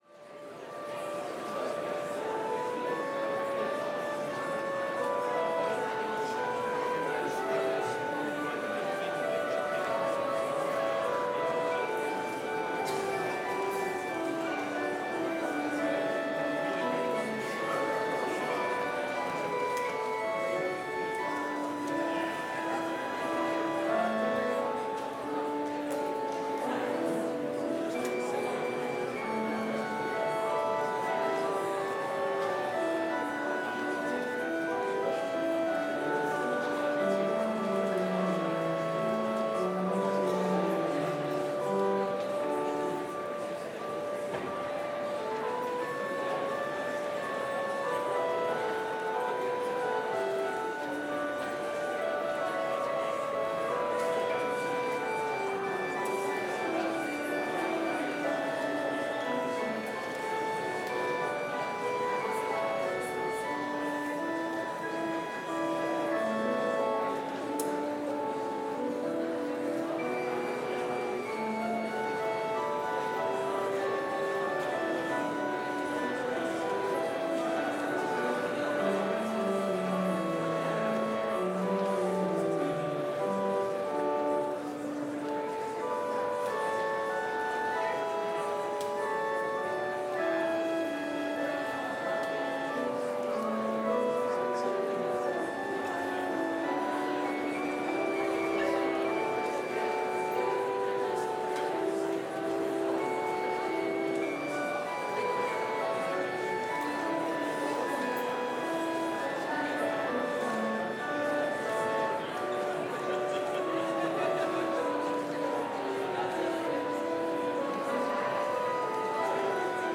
Complete service audio for Chapel - Friday, November 1, 2024
Order of Service Prelude Hymn 555 - Rise Again, Ye Lion-Hearted
Special Music Seminary Chorus: Thy Strong Word Devotion Hymn 555 - Rise Again, Ye Lion-Hearted View vv. 3, 4 Blessing Postlude